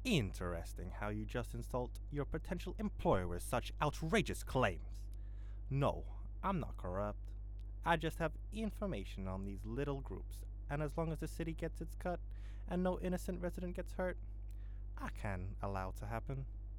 Voice Lines
Interesting how you_ll just insult your potential employer with such outreagous claims.wav